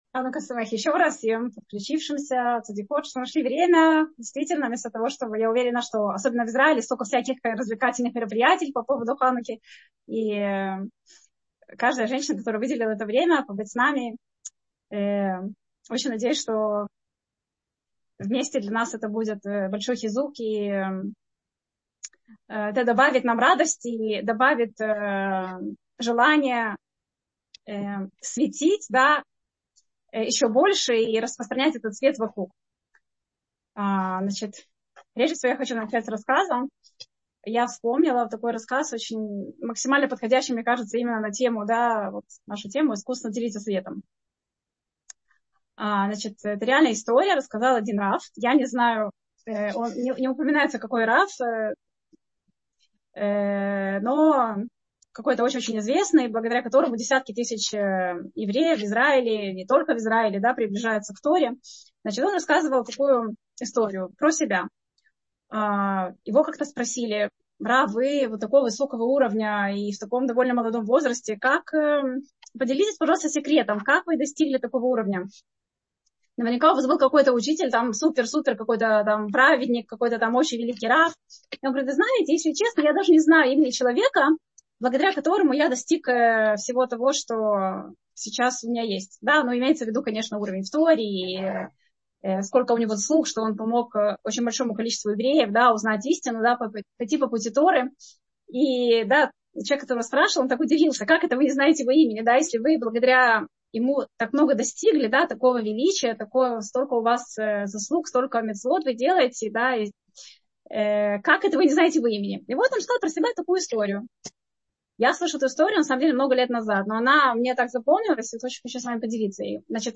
Утреннее Zoom ток-шоу «Утро с Толдот» приглашает вас на наш традиционный ханукальный Зум-марафон с кратким «спринт»-включением наших лекторов каждый день праздника.